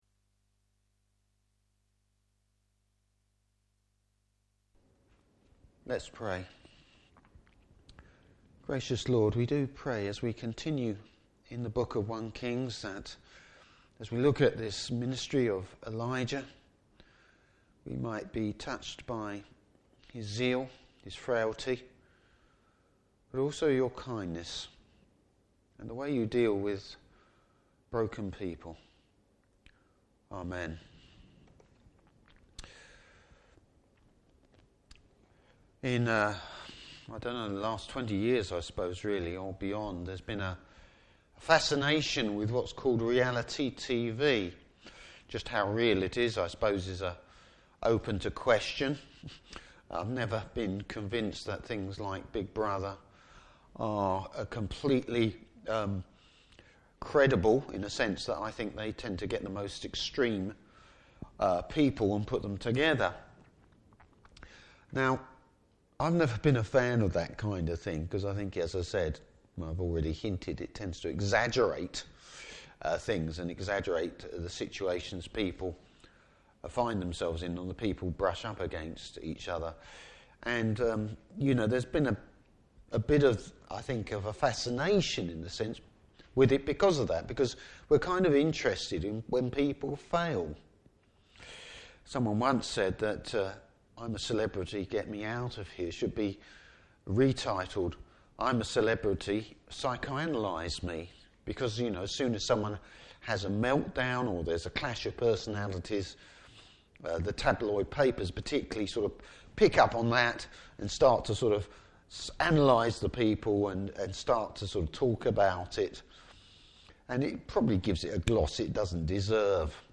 Service Type: Evening Service Bible Text: 1 Kings 19:1-18.